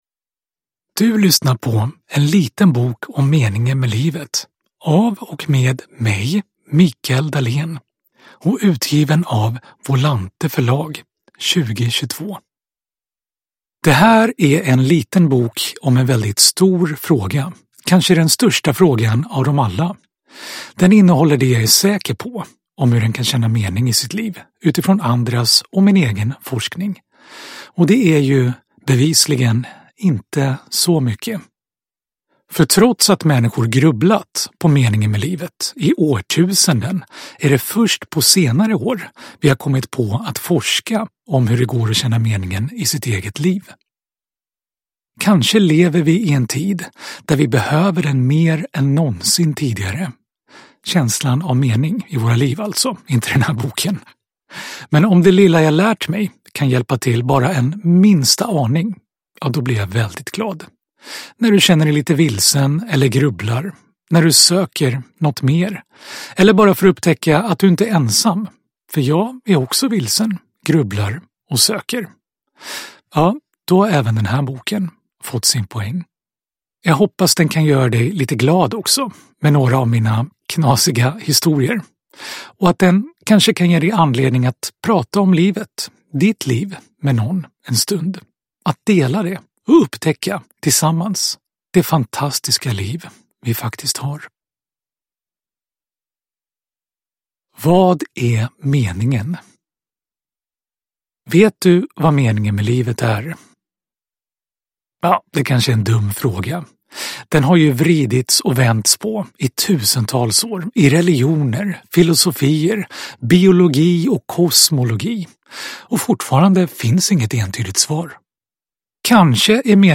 En liten bok om meningen med livet – Ljudbok – Laddas ner